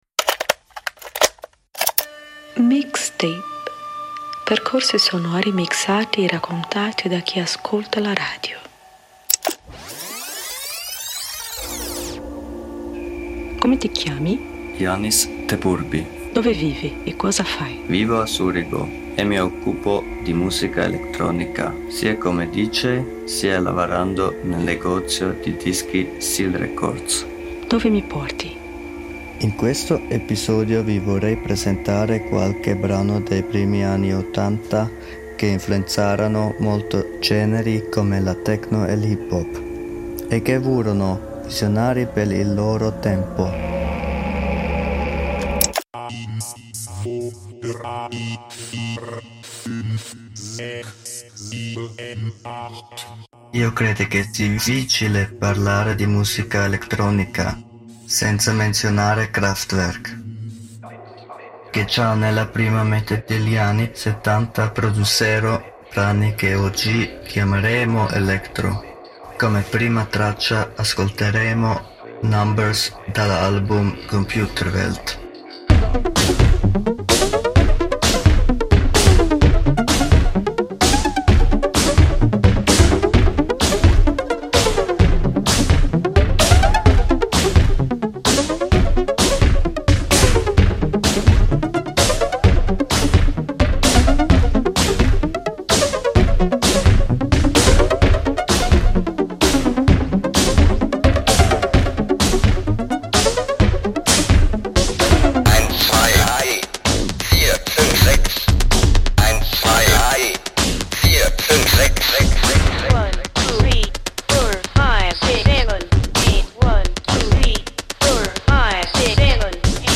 musica elettronica d’avanguardia